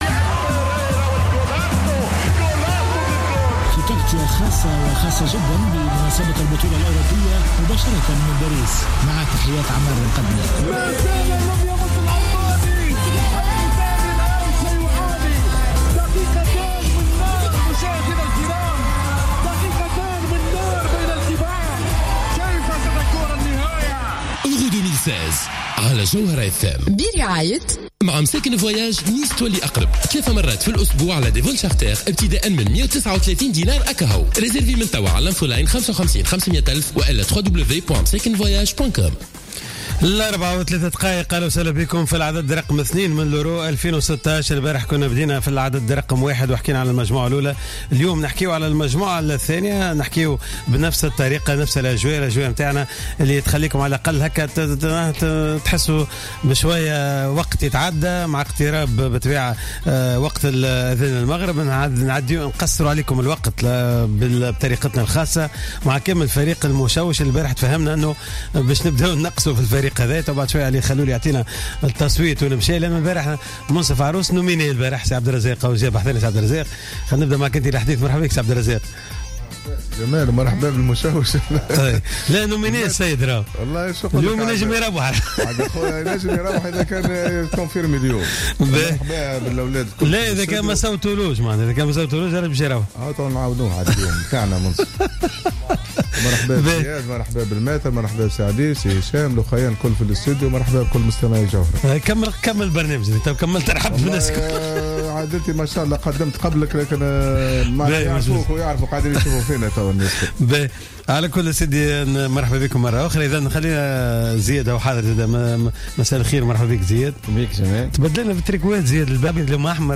مباشرة من باريس.